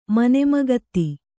♪ mane magatti